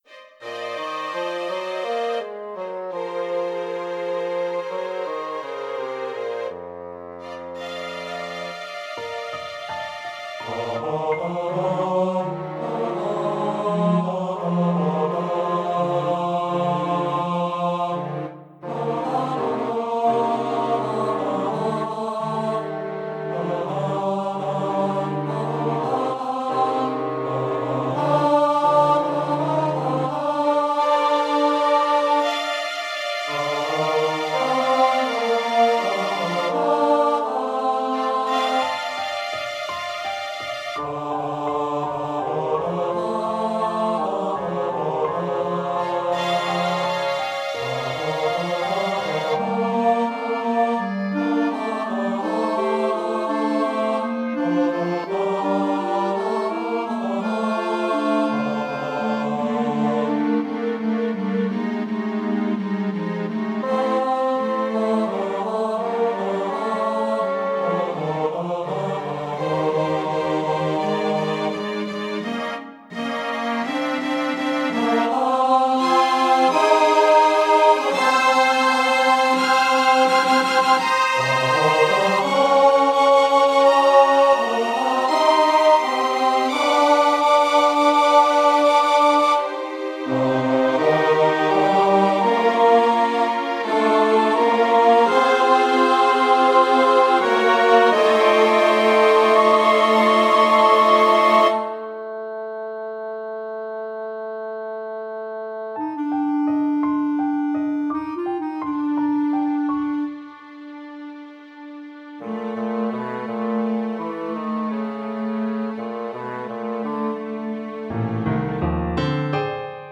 Speaking and Singing (Baritone)
SATB Chorus - Singing